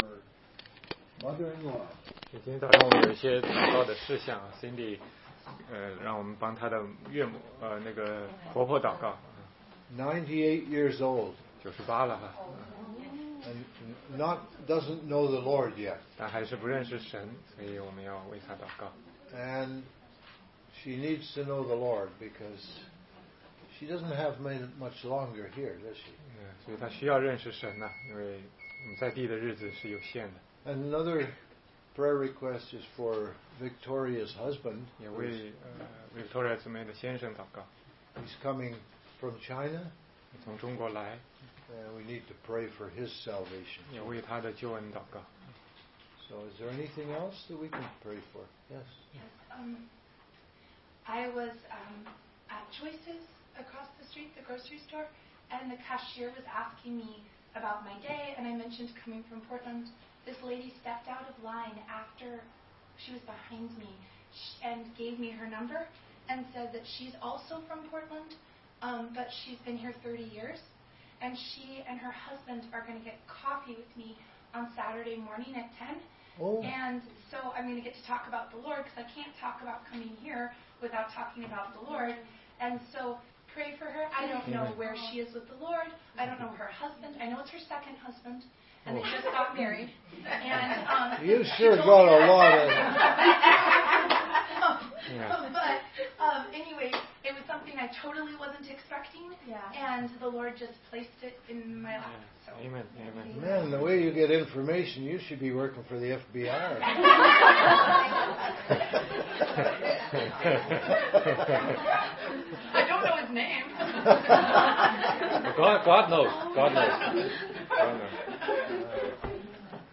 16街讲道录音 - 怎样才能读懂圣经系列之五